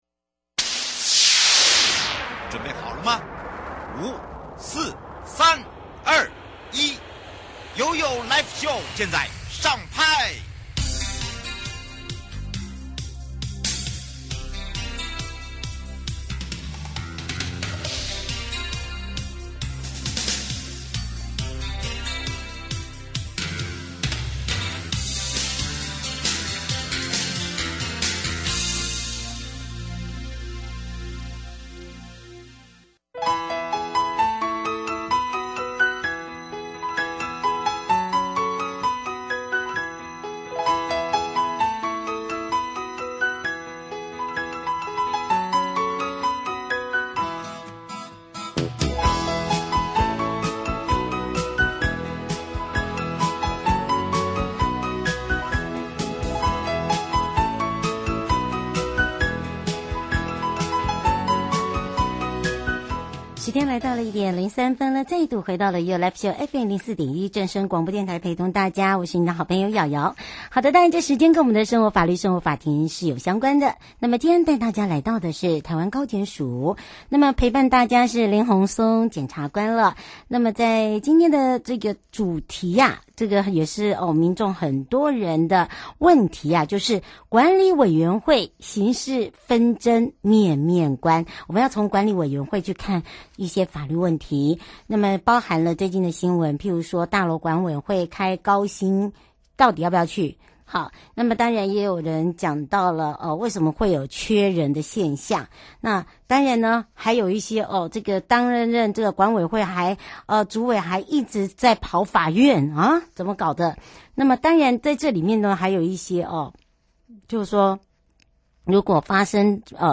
受訪者： 1. 台灣高檢署林宏松檢察官 2. 法務部保護司黃玉垣司長 節目內容： 1.